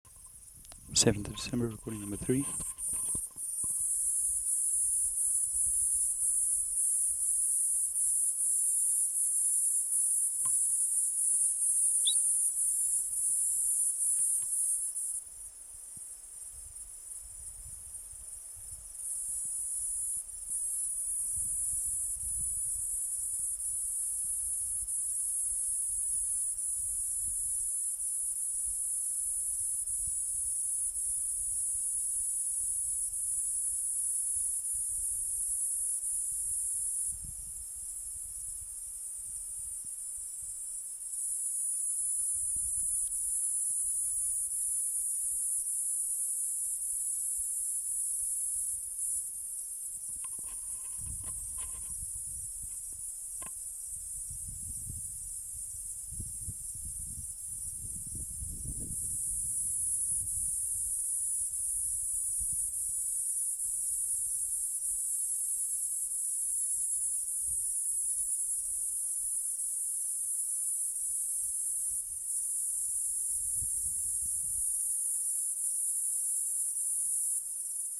Platypleura sp.
MHV 1412 Platypleura sp Chikongawa Forest Rec 2.wav